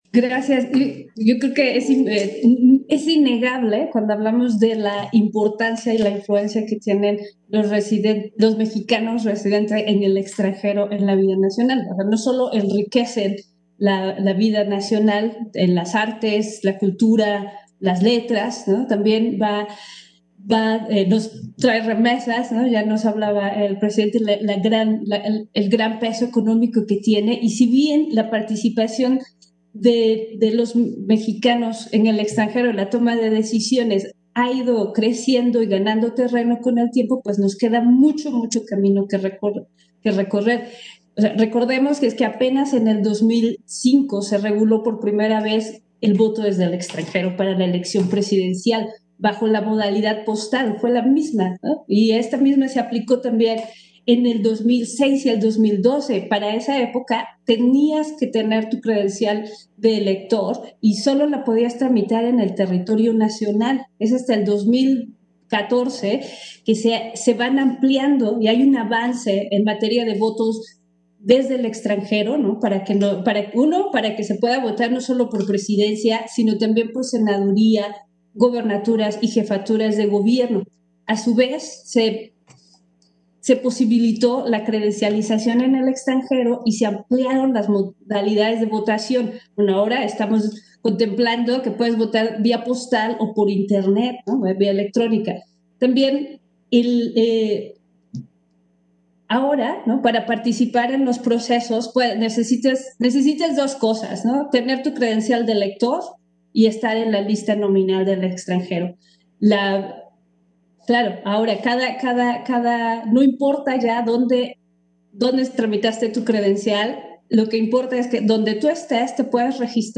Intervención de Norma de la Cruz, en el 1er. Conversatorio, Reflexiones en torno a la importancia de la participación política de las y los mexicanos residentes en el extranjero